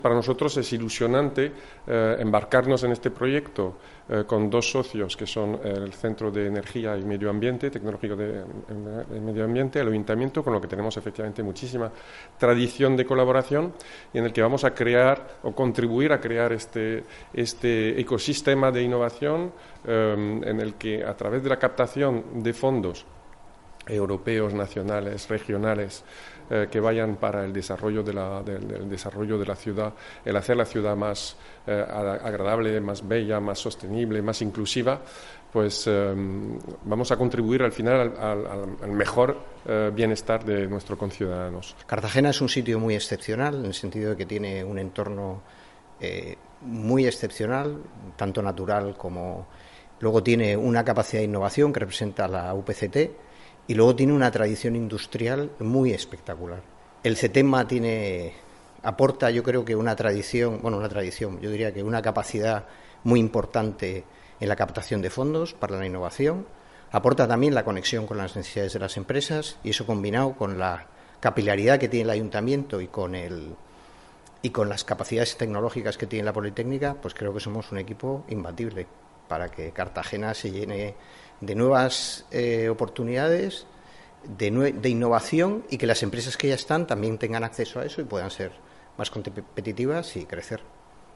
Enlace a Declaraciones de la alcaldesa, Noelia Arroyo, sobre proyecto Helix